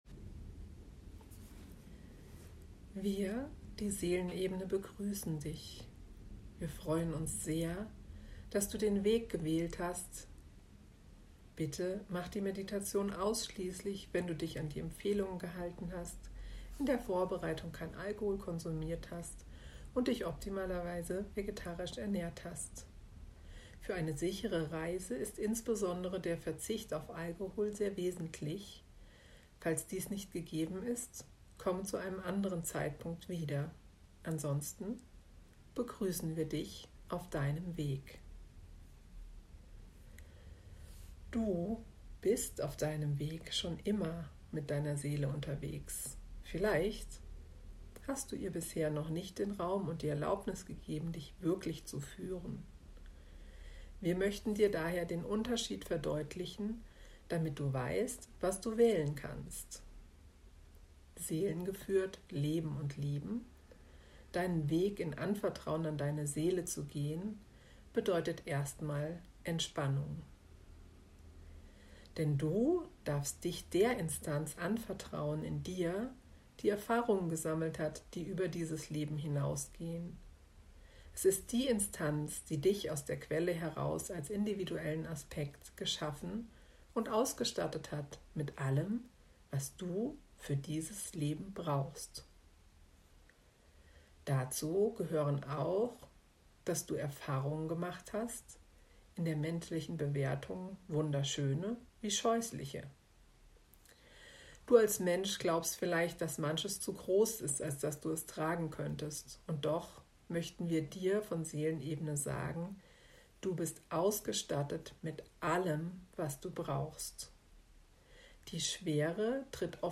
Im ersten Audio erhältst du eine kurze Einführung.